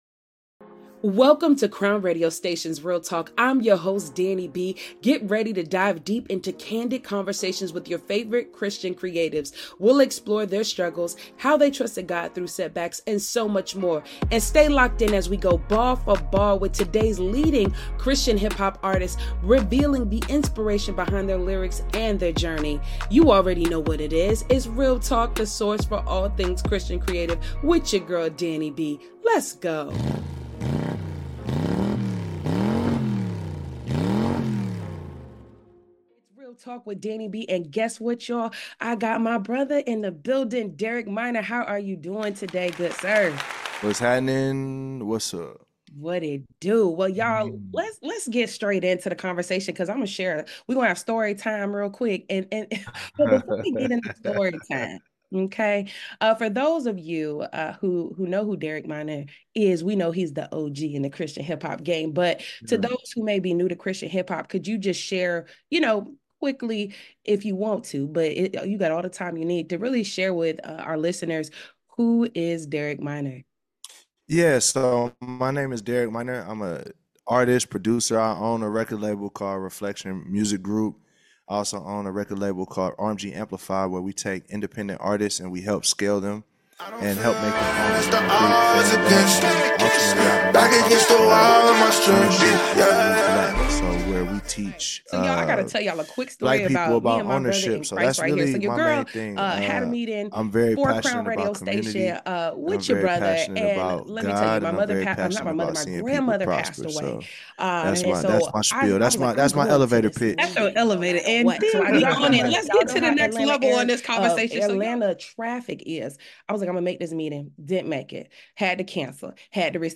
Exclusive Interview w/ Derek Minor